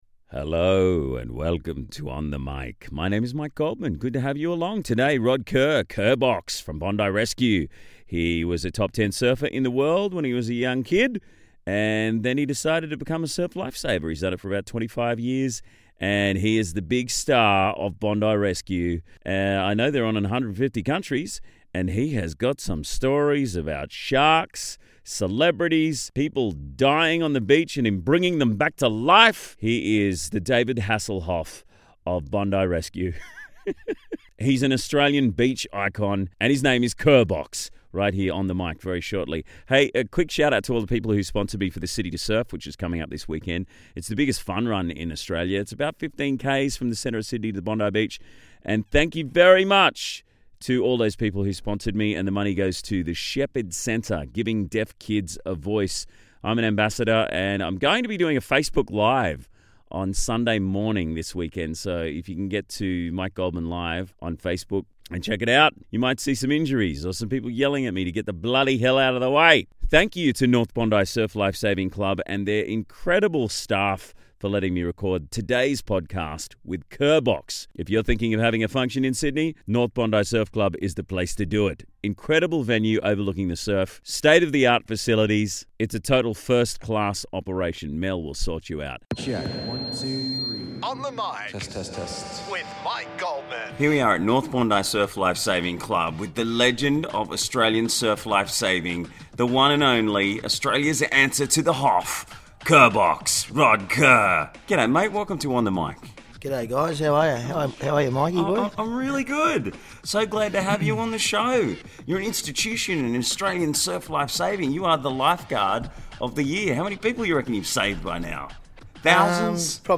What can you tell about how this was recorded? Recorded at the new North Bondi surf life saving club where I am a member.